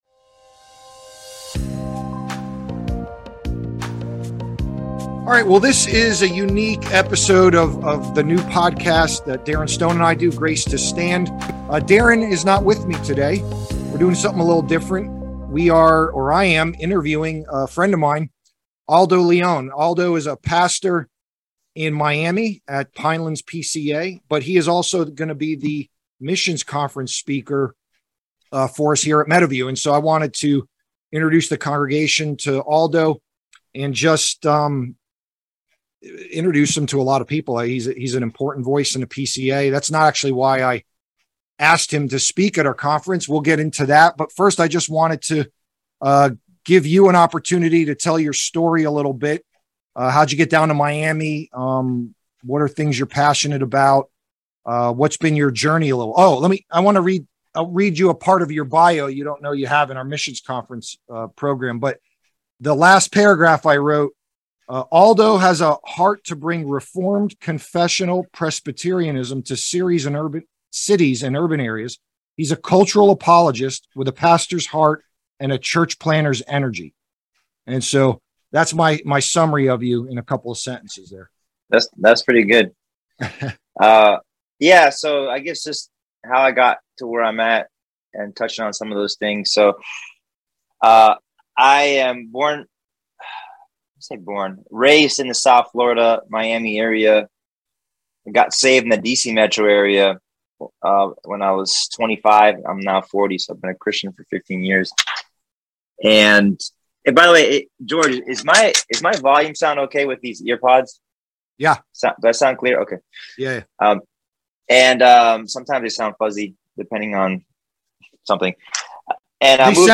& A Conversation on Missions